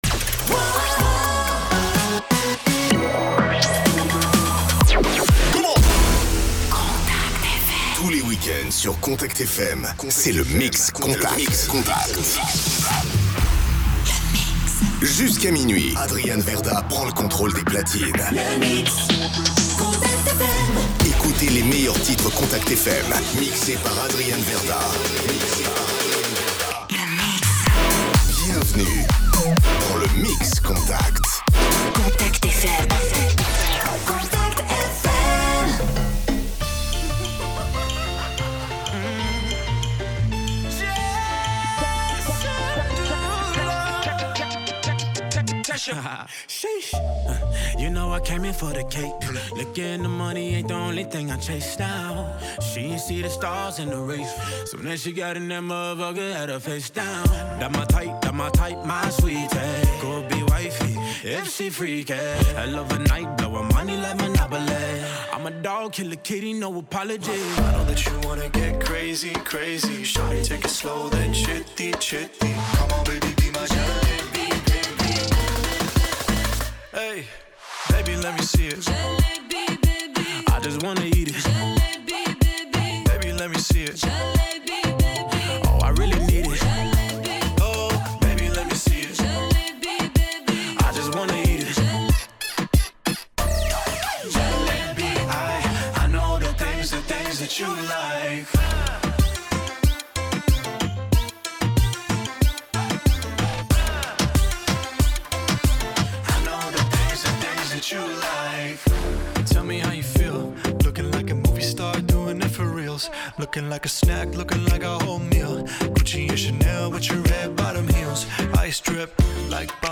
en mode Mix